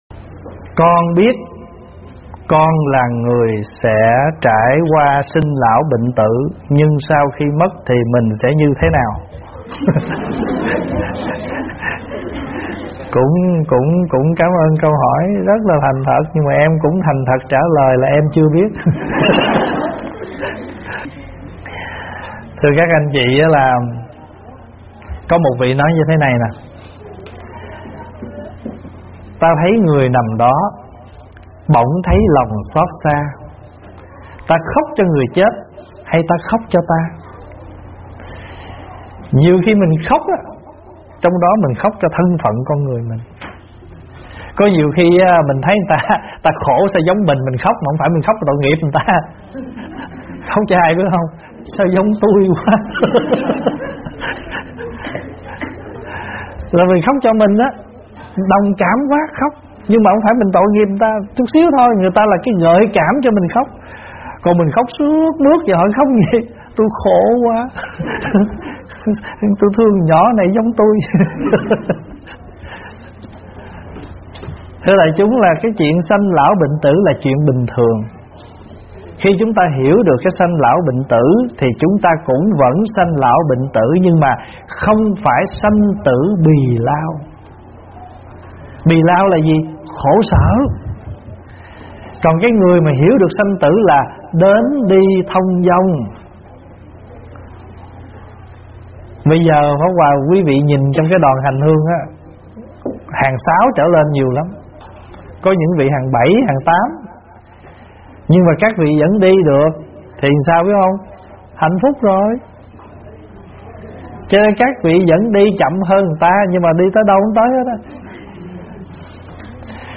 Mời quý phật tử nghe mp3 vấn đáp Sinh Lão Bệnh Tử